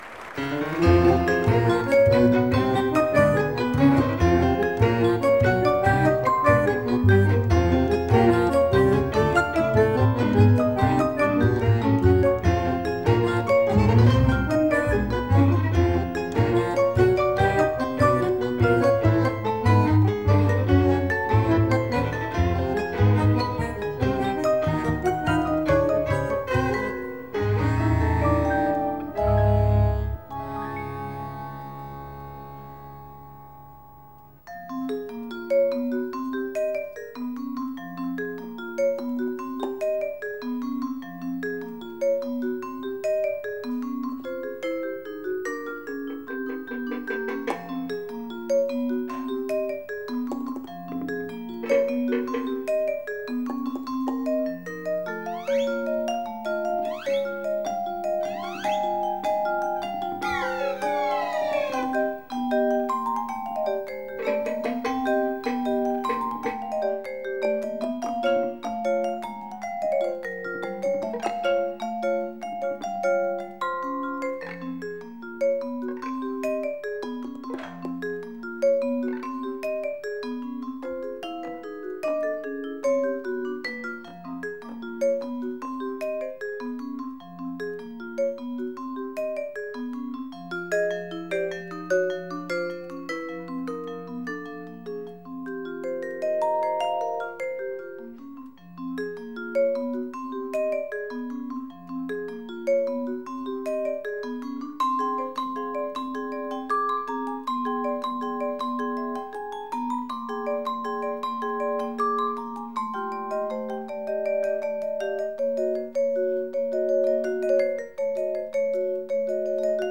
Жанр: Jazz, Latin
признанный североамериканский вибрафонист и композитор.
с нескладной темой и классическим обертоном